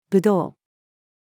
武道-female.mp3